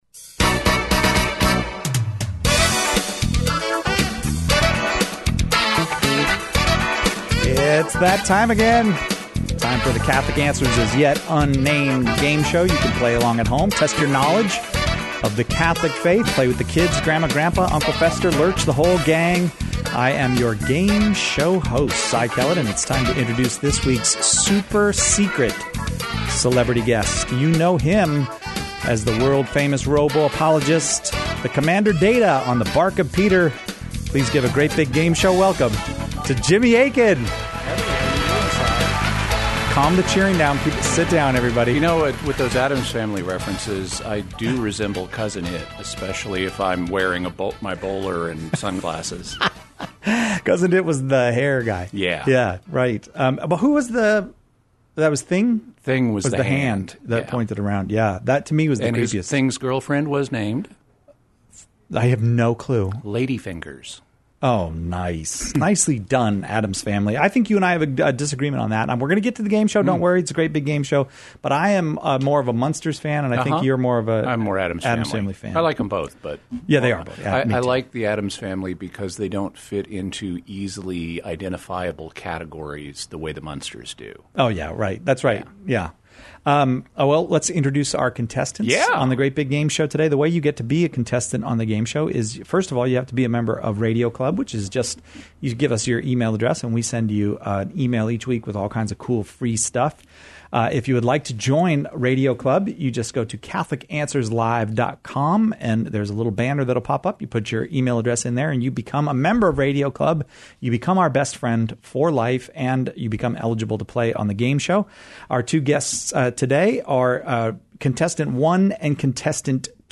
Catholic Answers Quiz Show